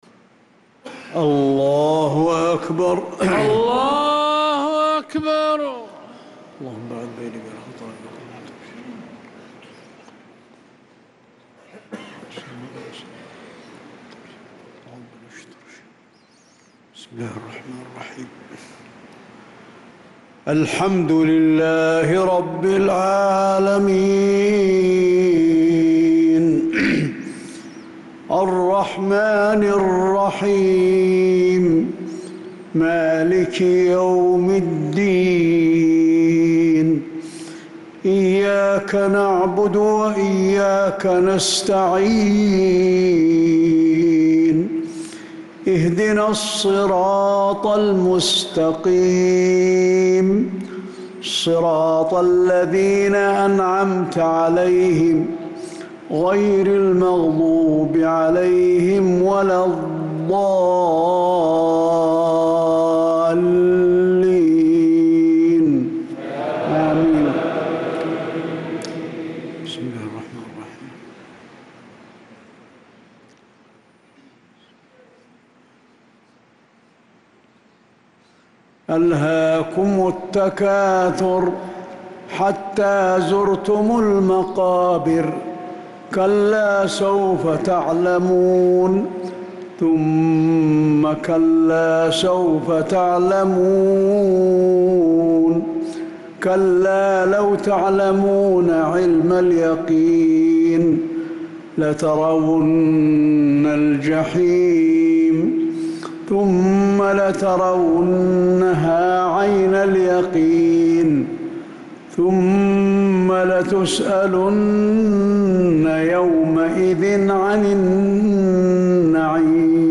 صلاة المغرب للقارئ علي الحذيفي 13 ربيع الآخر 1446 هـ
تِلَاوَات الْحَرَمَيْن .